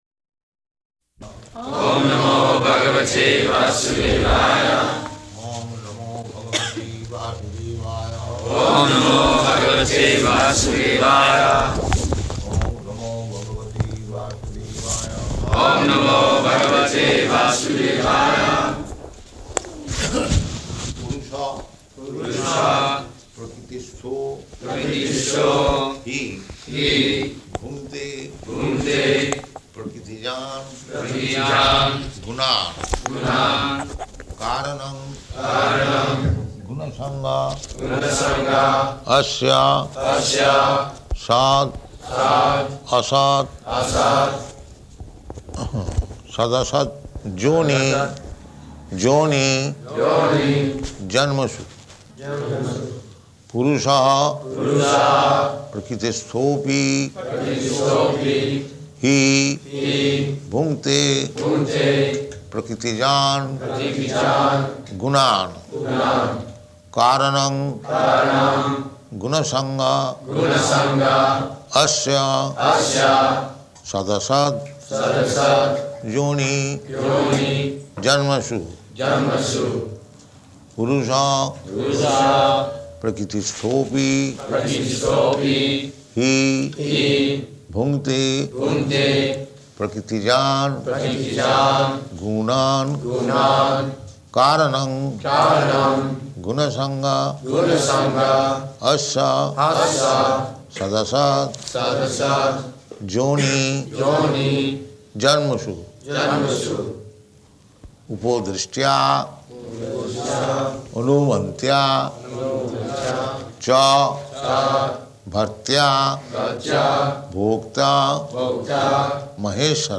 Bhagavad-gītā 13.22–24 --:-- --:-- Type: Bhagavad-gita Dated: June 25th 1974 Location: Melbourne Audio file: 740625BG.MEL.mp3 Prabhupāda: Oṁ namo bhagavate vāsudevāya.
[devotees repeat] [leads chanting of verse]